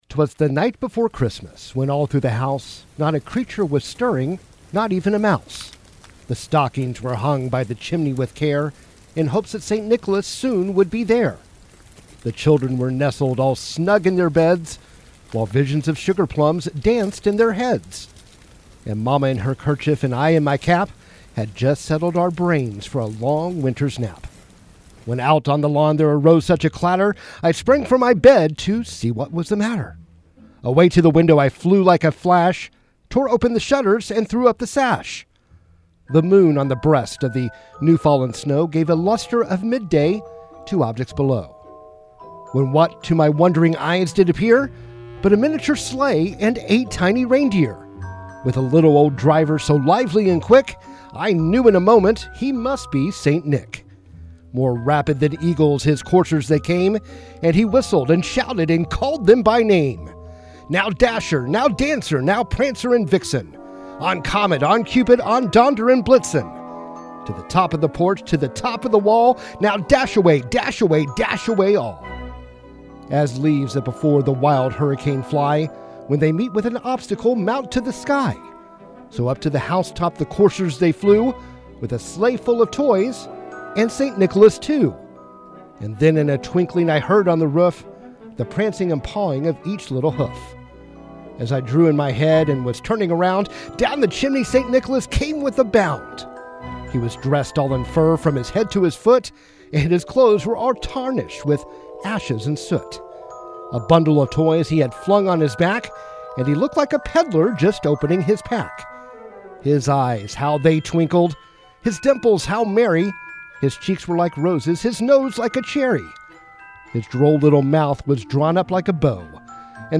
KWON Airs Special Reading of 'A Visit From St. Nicholas'
During the KWON 5 o' Clock News Hour, we will have a special reading of  "A Visit from St. Nicholas," also commonly known as "'Twas the Night Before Christmas."